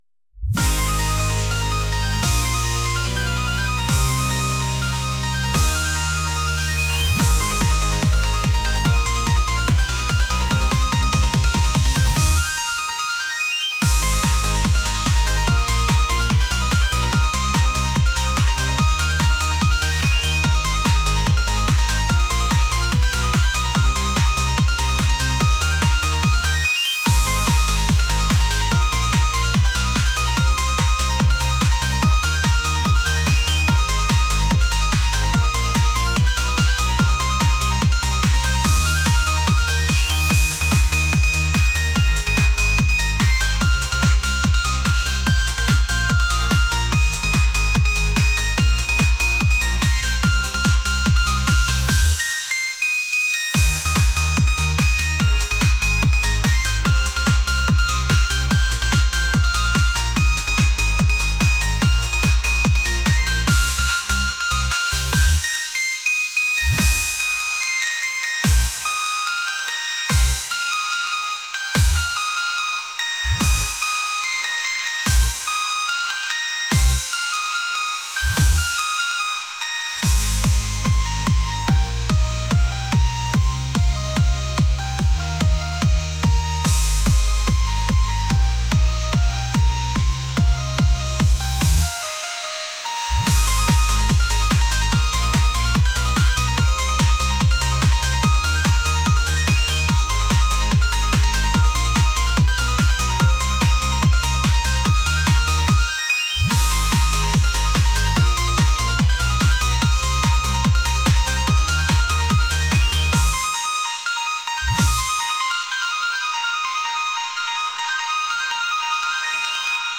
electronic | energetic | pop